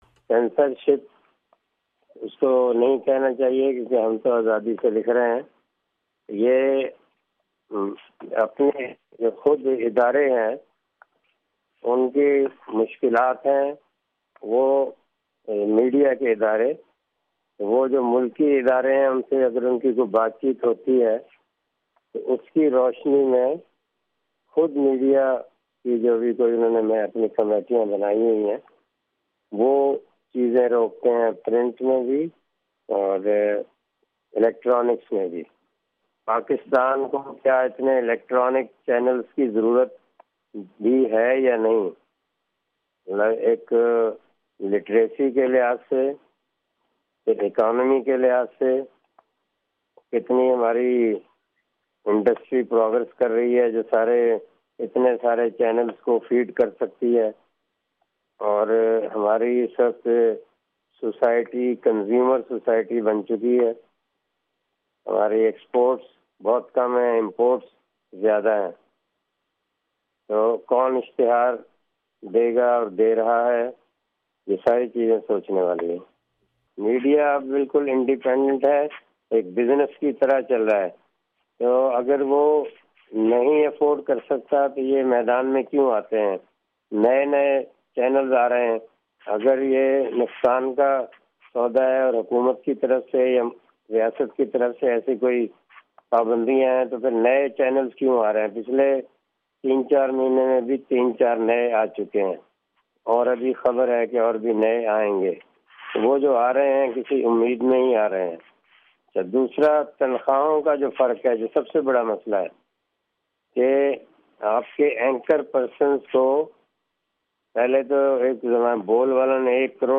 Mahmood Shaam Audio Interview